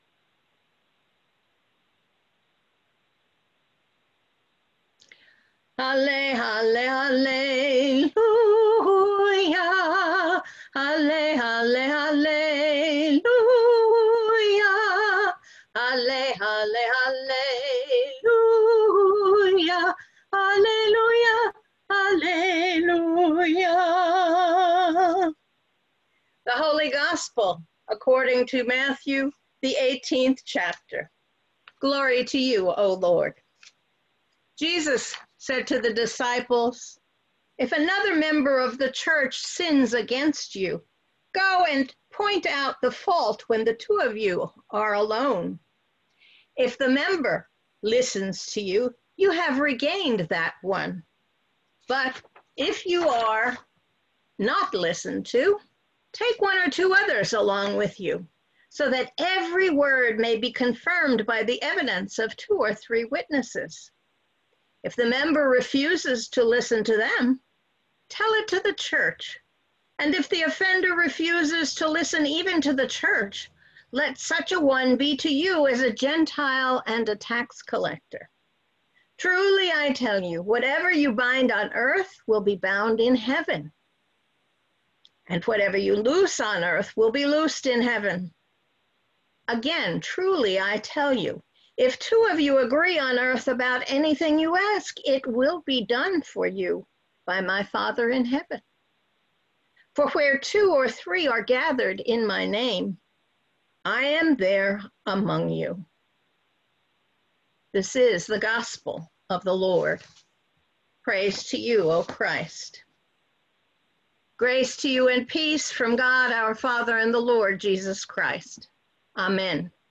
Sermons | Lutheran Church of the Epiphany and Iglesia Luterana de la Epifania